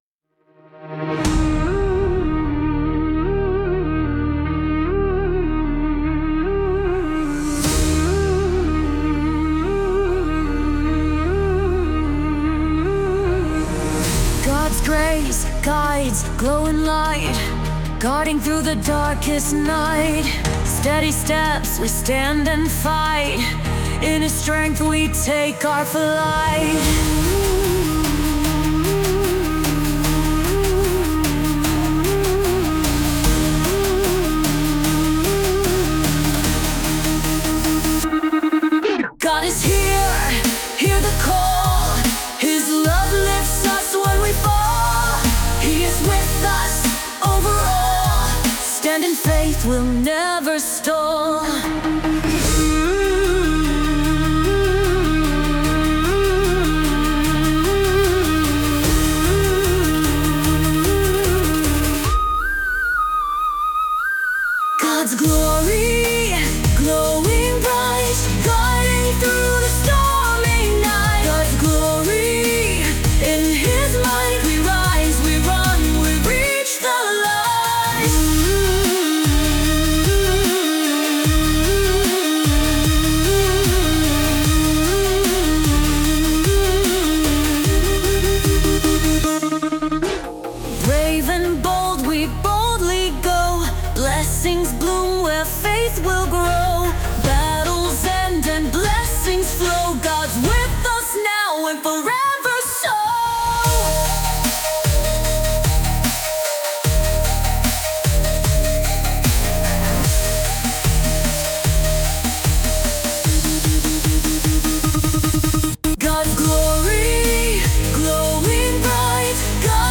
The following audio is a sample of A.I. generative technology.
There are no human artists involved in the audio. Everything is generated by A.I. the lyrics were also generated by A.I. but with specific prompts and direction along with human generated foundational lyrics.
by Lumen Audio